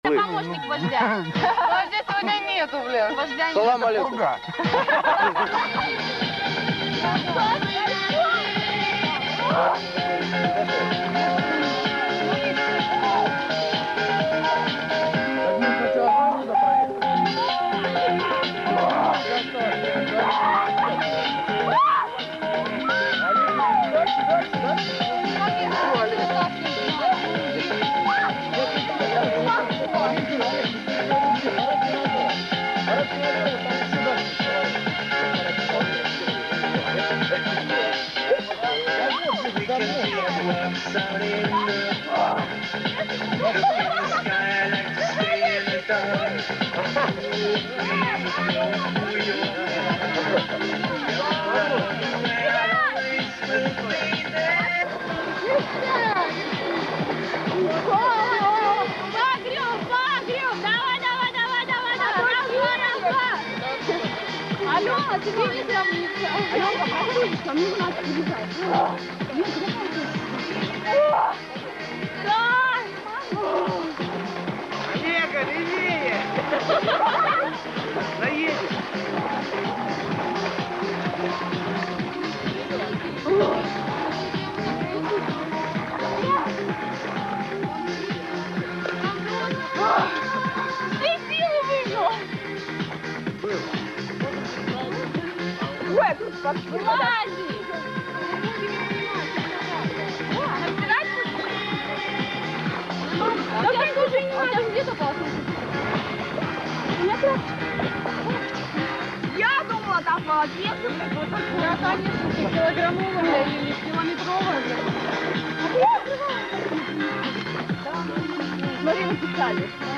помогите узнать кто исполняет песенку,слышал на пекнике в 97 г. ... так нравится,изв за кач. ну уж оч хочется вернутся в 97г.